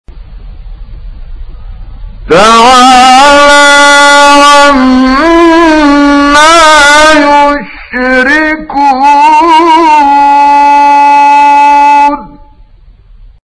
15 فراز از «کامل یوسف» در مقام بیات
گروه شبکه اجتماعی: فرازهای صوتی از کامل یوسف البهتیمی که در مقام بیات اجرا شده است، می‌شنوید.